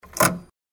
card_remove.mp3